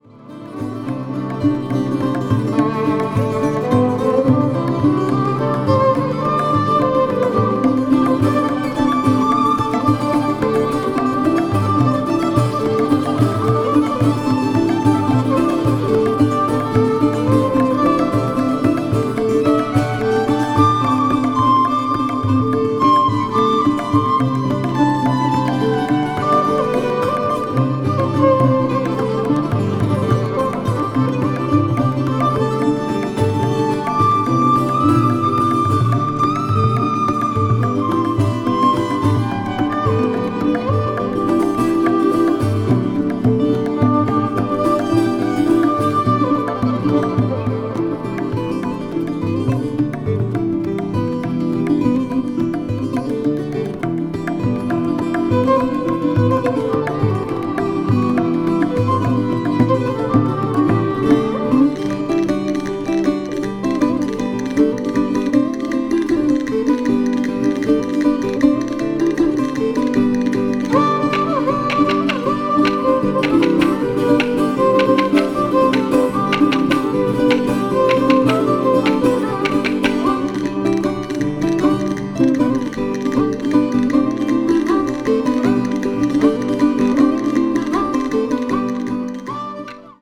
独特のエコー処理が施されたたおやかな音響が心地良いB5やB6がお薦め。